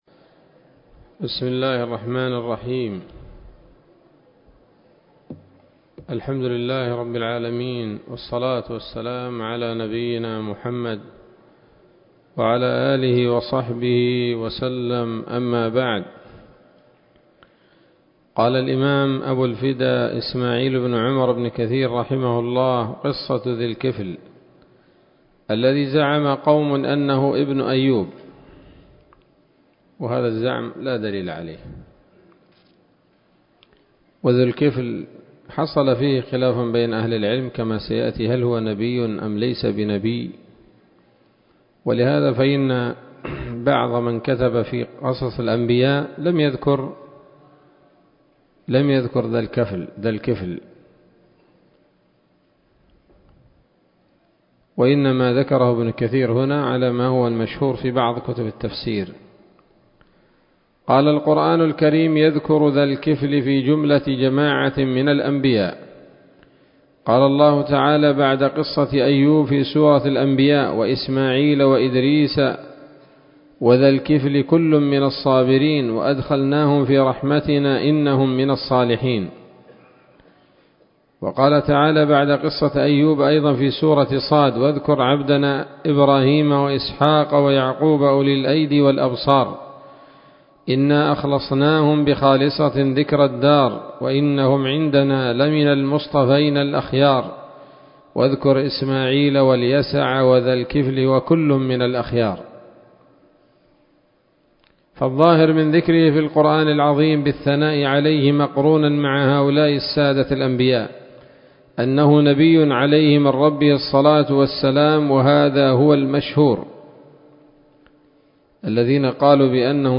الدرس السادس والسبعون من قصص الأنبياء لابن كثير رحمه الله تعالى